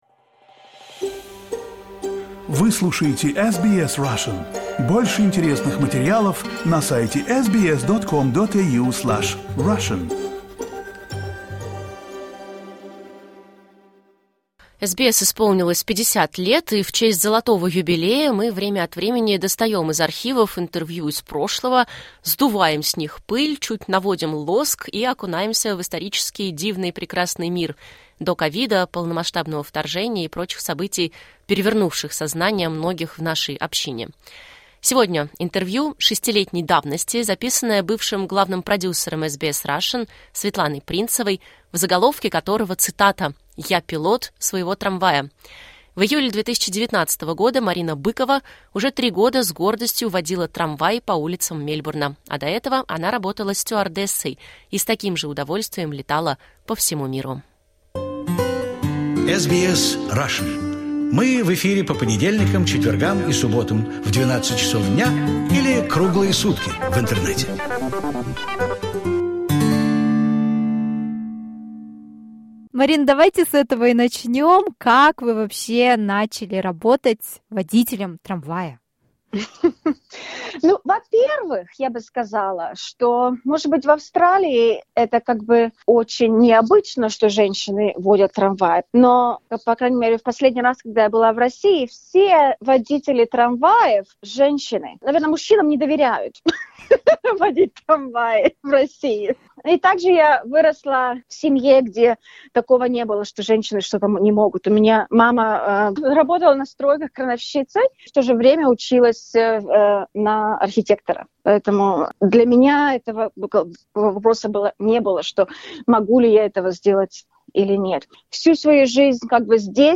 Это архивное интервью 2019 года.